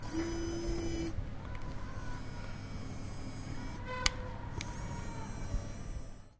摄像机